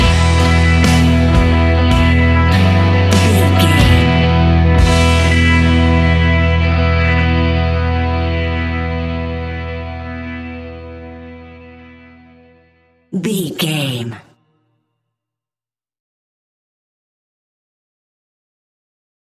Ionian/Major
indie pop
fun
energetic
uplifting
cheesy
instrumentals
upbeat
rocking
groovy
guitars
bass
drums
piano
organ